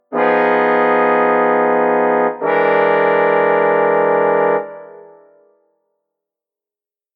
Nesta obra é apresentada uma progressão de 2 acordes que é considerada por diversos teóricos musicais como aquela que representa o momento na história da música européia onde esta transcende a tonalidade.
O primeiro acorde é conhecido como Tristan chord (acorde de Tristão).
Ao invés desta progressão representar uma resolução, um relaxamento tonal, normalmente esperado após uma tensão (no caso, o trítono, nas notas mais graves do acorde de Tristão), este progride para outro acorde também com tensão (que também possui trítono, entre a segunda e a terceira nota do segundo acorde), como se este representasse a permutação de um conflito, como algo que a princípio inquieta e que parece tentar se resolver mas que na verdade apenas transmuta de natureza, para outros contexto tanto quanto ou ainda mais inquietante.
ti-chord.mp3